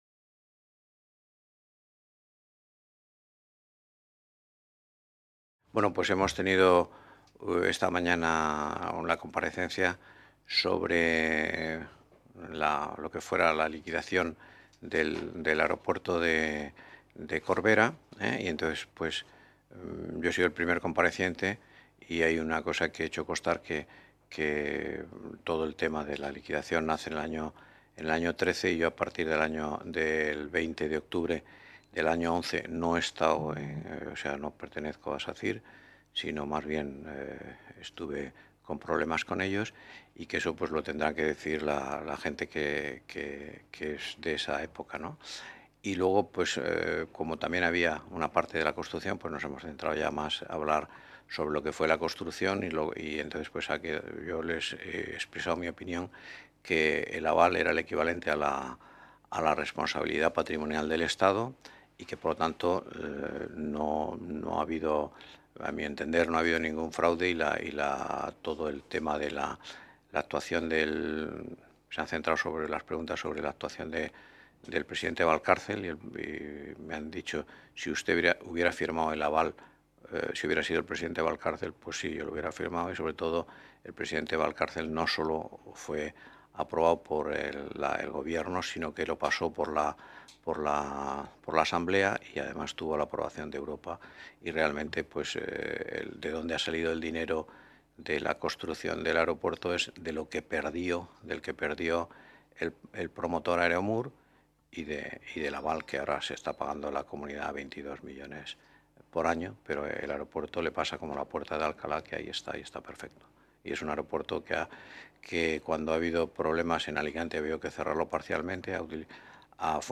Rueda de prensa tras la Comisión Especial de Investigación sobre la liquidación fallida por daños y perjuicios en la construcción y explotación del Aeropuerto Internacional de la Región de Murcia | Asamblea Regional de Murcia